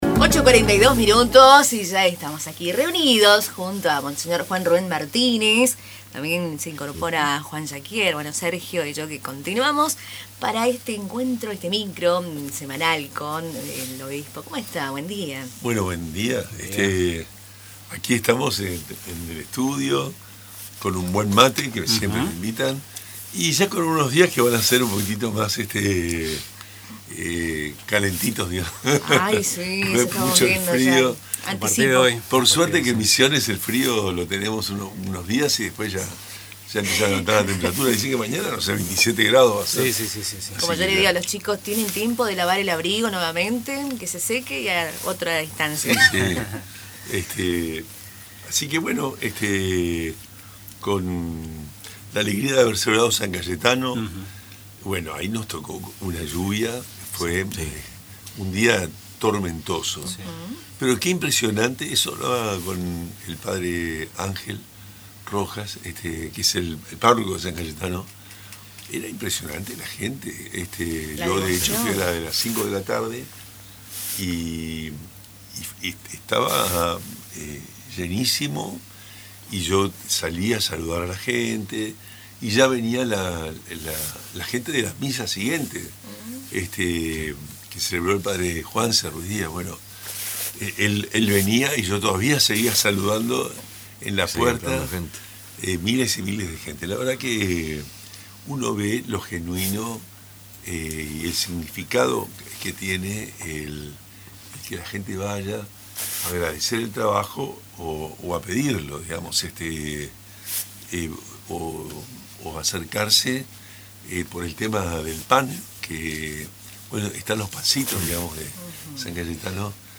Durante su visita habitual a los estudios de Radio Tupa Mbae, el obispo de la diócesis de Posadas, monseñor Juan Rubén Martínez, abordó varios temas de interés, entre ellos la propuesta de reducir la edad de imputabilidad de menores a 13 años.